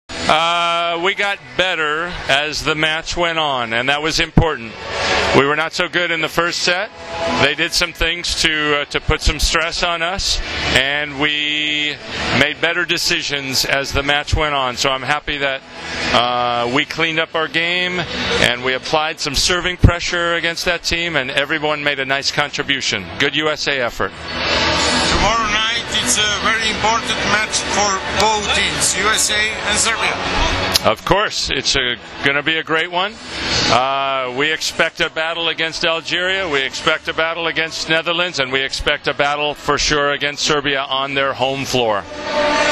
IZJAVA KARČA KIRALJA, TRENERA SAD